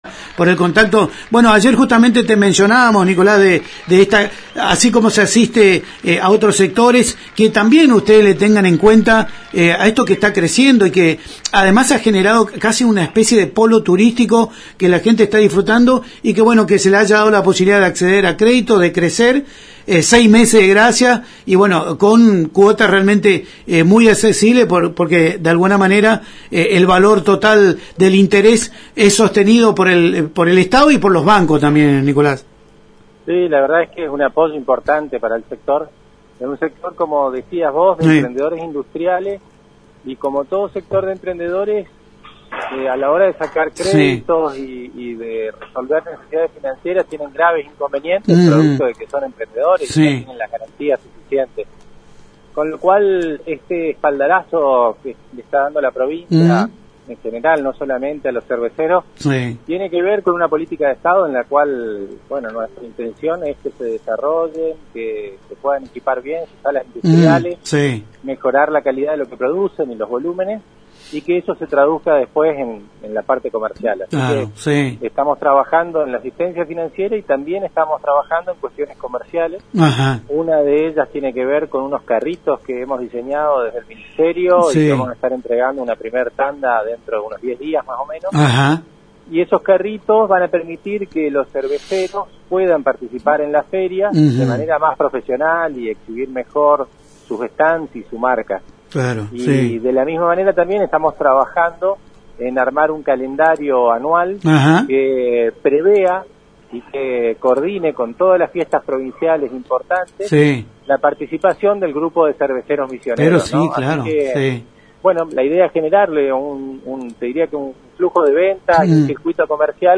El ministro de Industria, Nicolas Trevisan en comunicación telefónica con ANG y Multimedios Génesis, manifestó que la entrega de préstamos para realizar inversiones e impulsar otros proyectos a emprendedores, ralizada ayer en Eldorado, refleja una política de Estado que considera el apoyo financiero a proyectos productivos, pero también la etapa de comercialización y la disposición de infraestructiras acordes a un proyecto a largo plazo. La entrega corresponde a una línea crediticia especial con tasa subsidiada por la provincia anunciada el año pasado por el Gobernador Herrera Ahuad.